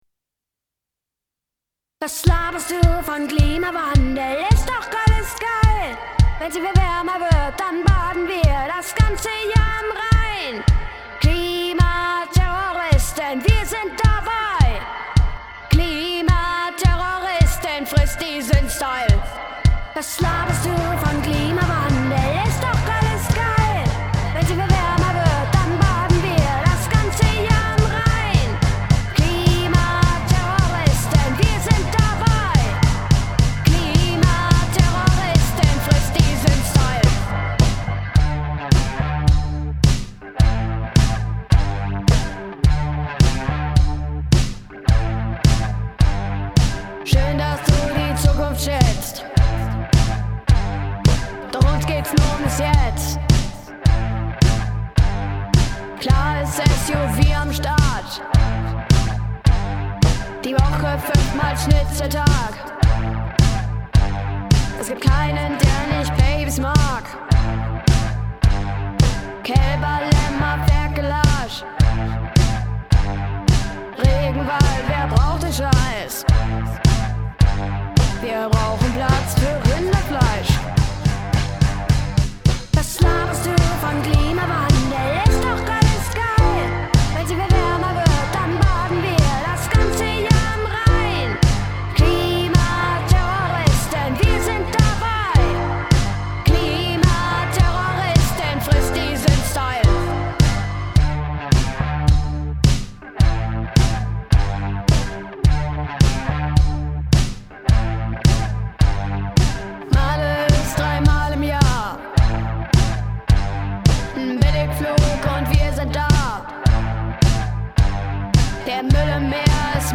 Unsere TeenBand „Die fetten Boxen“ konnte mit ihrem selbst geschriebenen Song „Klimaterroristen“ beim Cologne Song Contest 2023 einen großen Erfolg feiern.